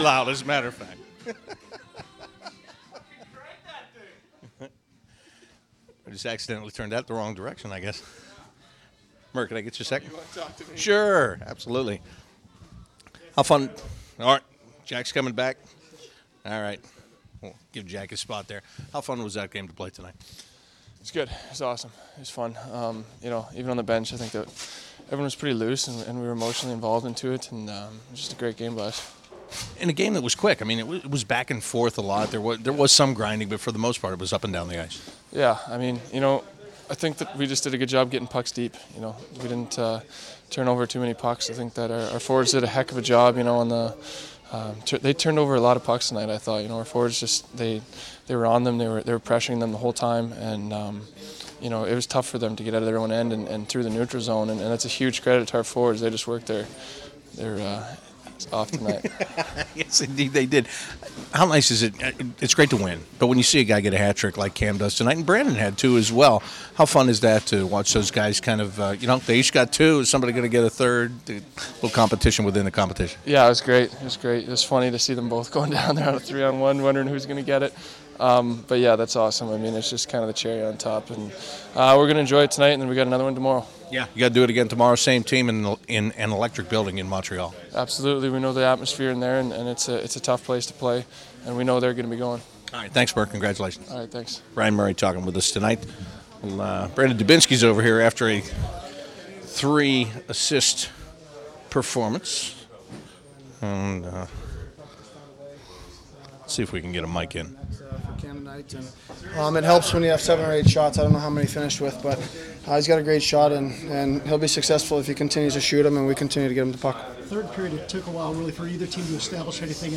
Ryan Murray, Brandon Dubinsky, Joonas Korpisalo and Cam Atkinson talk about their 5-2 home victory over the Montreal Canadiens